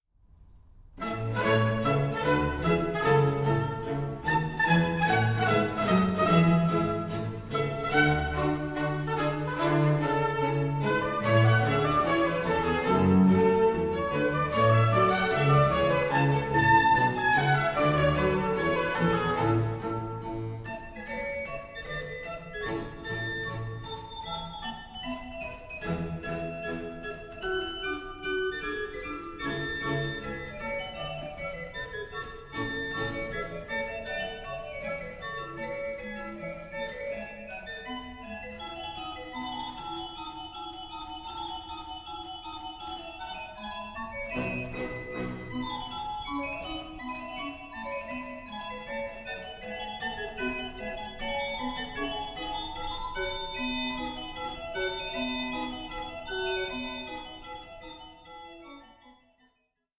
6) Bourree 2:33